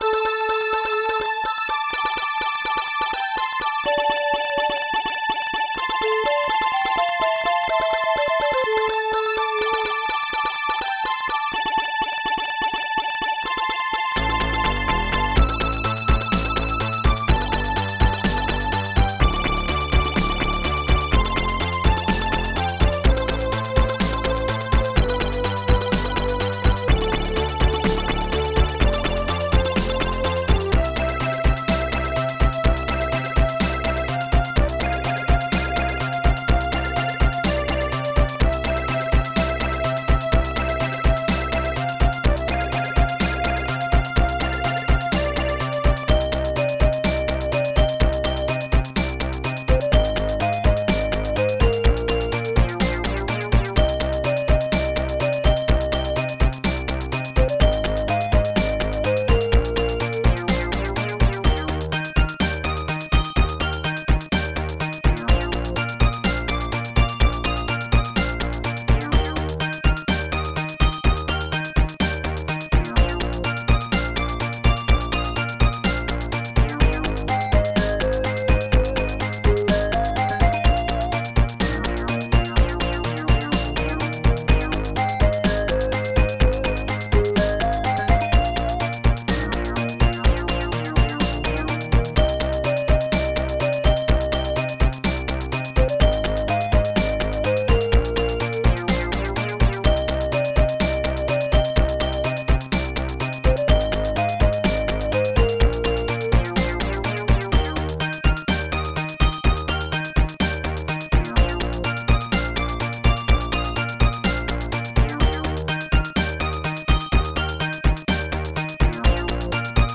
Protracker and family
ST-01:KORGBASS
ST-01:PINGBELLS
ST-01:PANFLUTE
ST-01:STRINGS3
ST-01:HIHAT1
ST-01:POPSNARE2
ST-01:BASSDRUM1